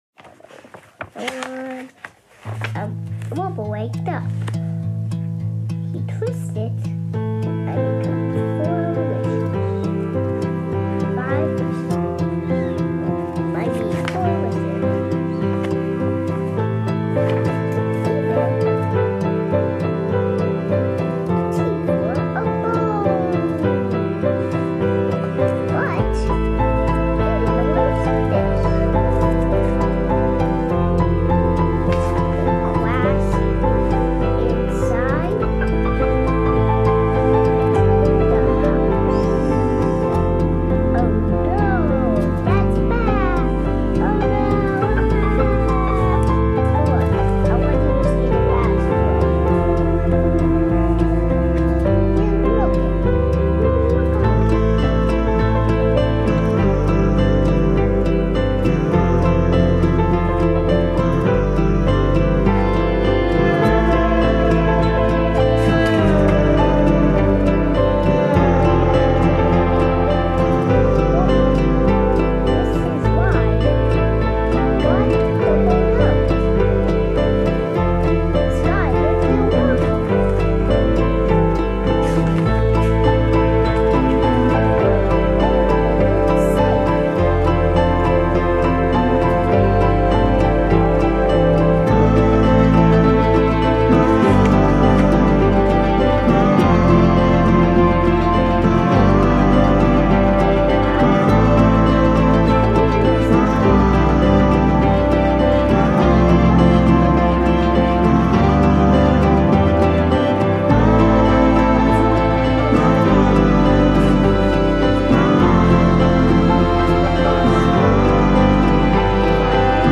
Tags2020s 2025 Canada indie Rock